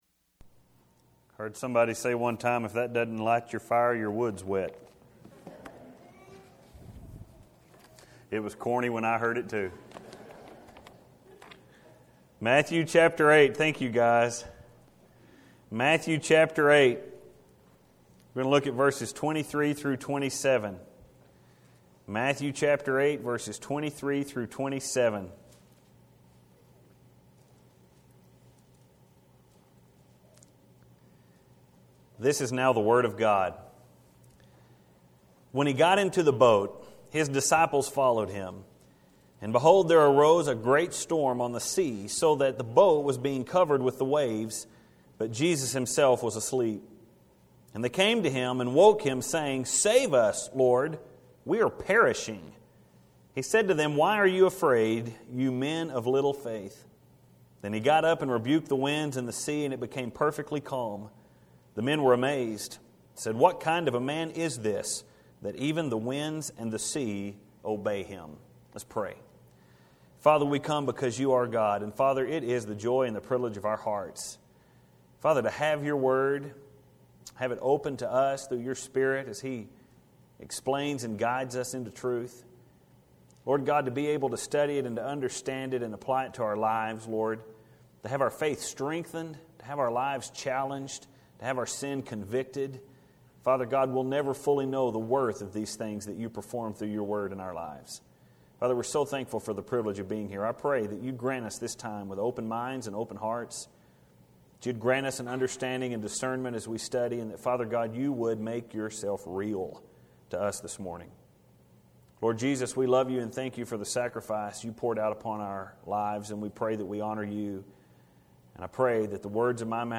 And without getting into a whole different sermon on the creation story, There are two main things that I want you to recognize in that passage. 1) Everything God created was good. 2) Everything God created was placed in subjection to man.